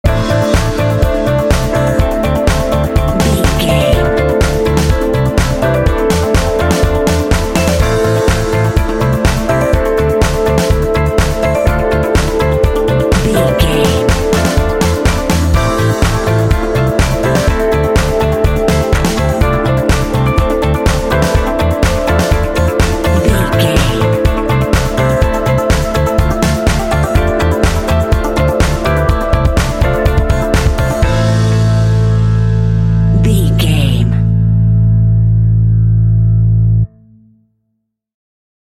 Aeolian/Minor
funky
smooth
groovy
driving
synthesiser
drums
strings
piano
electric guitar
bass guitar
electric piano
alternative rock